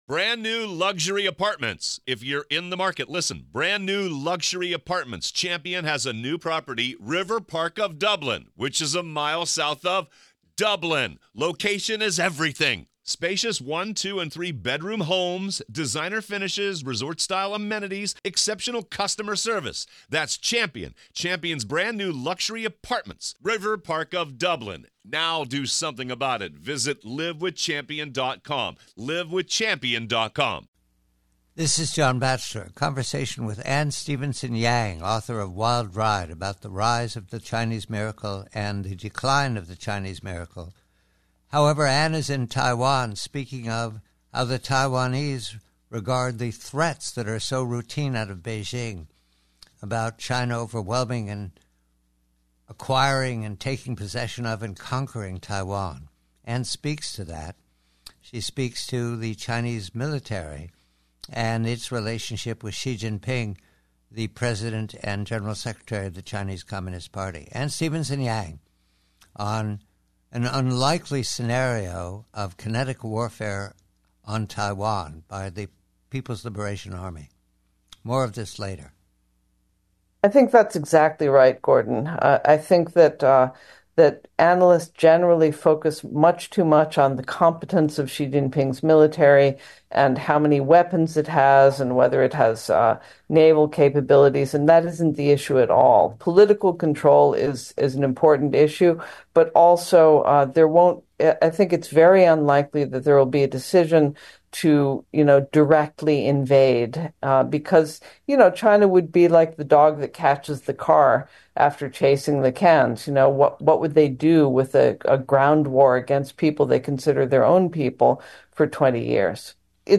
reporting from Taipei, comments on the low probability of a PRC/PLA attack on Taiwan.